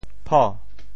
叵 部首拼音 部首 口 总笔划 5 部外笔划 2 普通话 pǒ 潮州发音 潮州 po2 文 中文解释 叵〈副〉 (会意。“